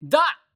戦闘 掛け声 気合い ボイス 声素材 – Battle Cries Voice